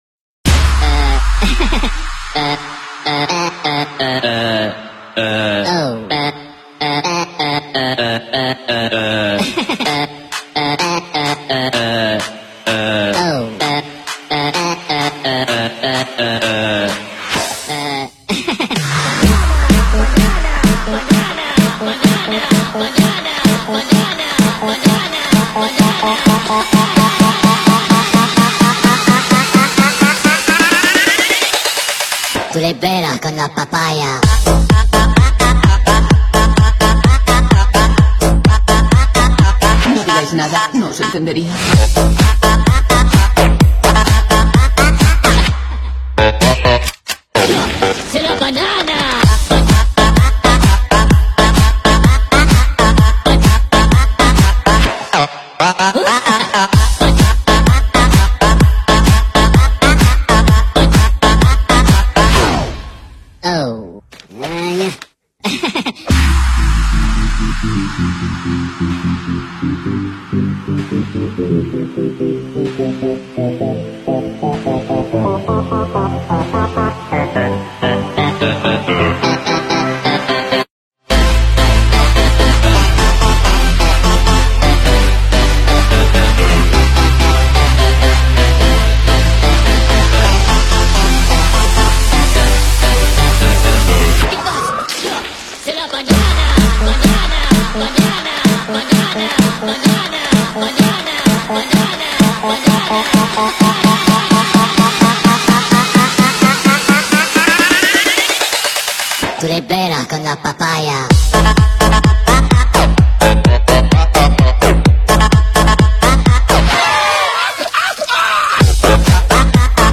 • Качество: 245, Stereo
прикольная музыка
Смешно
Очень прикольная музыка